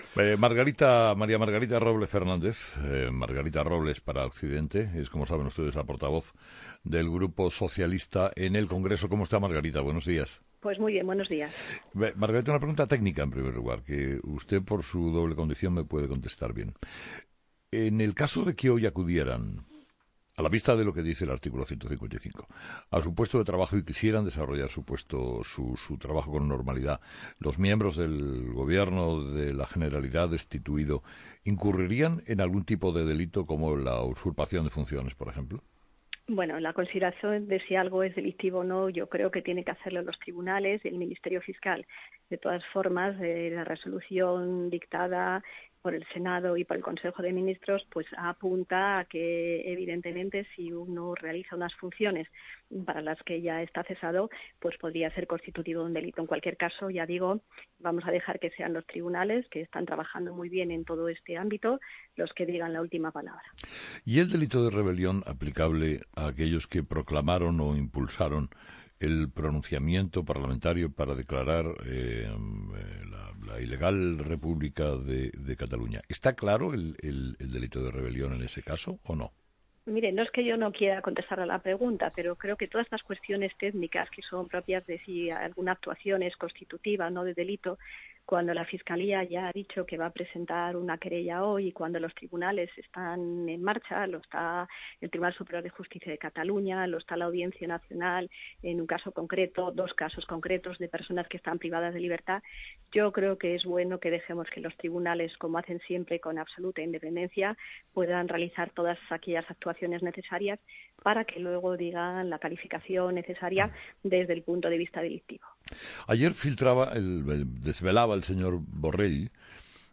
Entrevista con Margarita Robles
Entrevistado: "Margarita Robles"